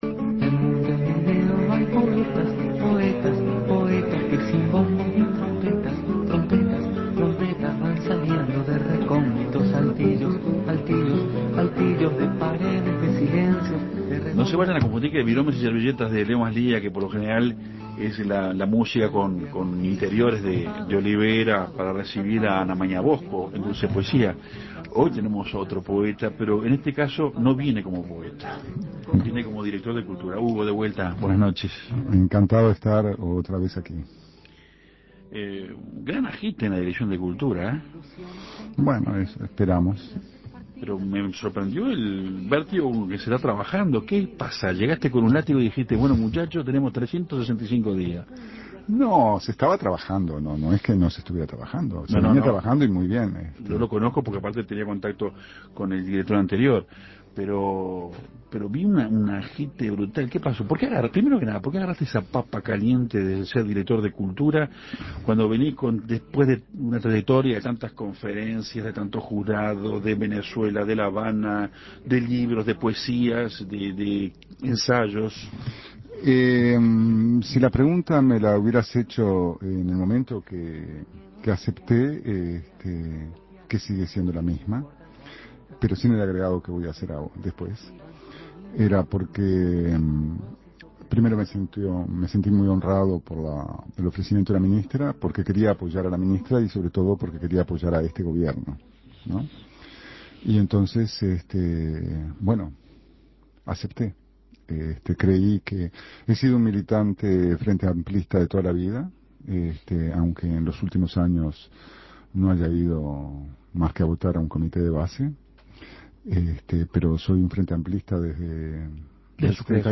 Hugo Achugar, director nacional de Cultura, fue entrevistado en Café Torrado en donde habló de la tarea de gestionar cultura en nuestro país.